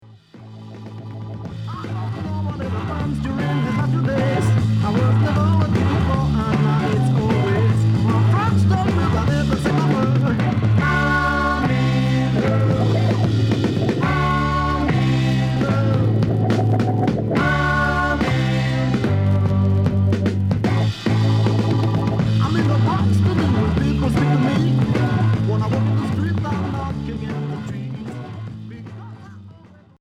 Heavy rock pop